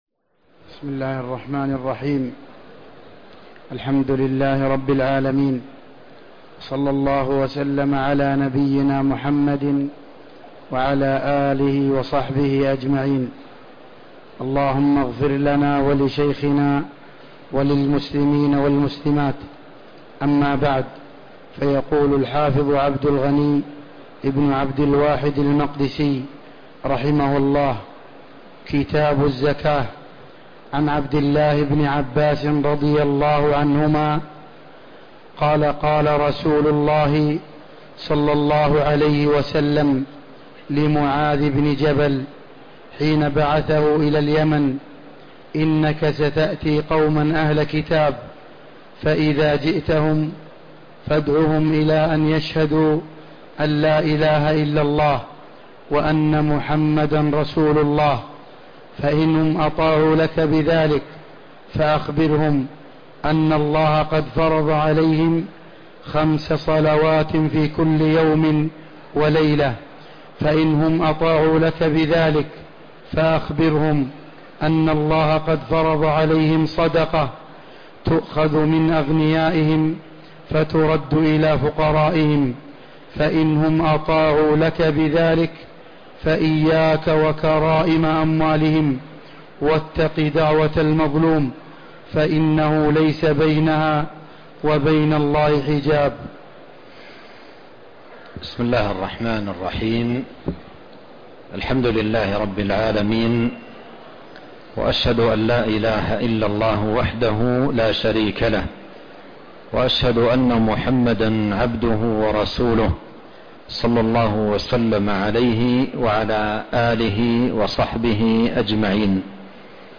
شرح عمدة الأحكام في معالم الحلال والحرام عن خير الأنام الدرس 41